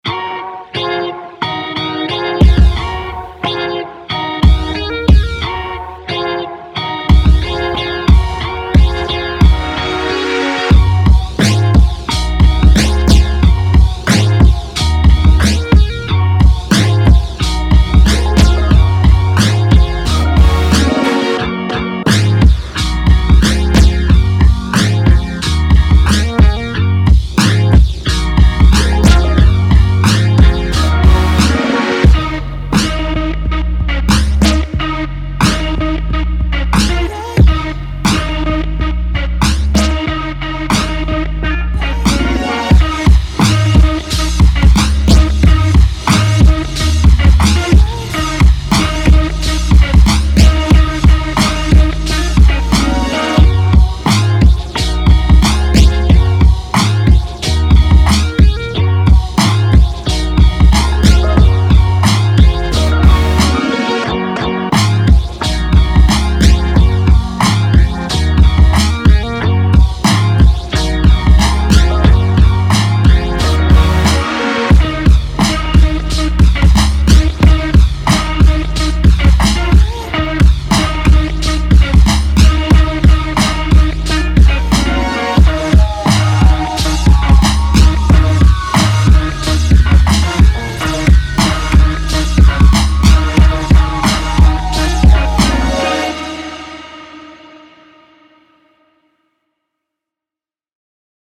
BOUNCY CHILL-HOP
Laid back / Relaxed / Positive / Funky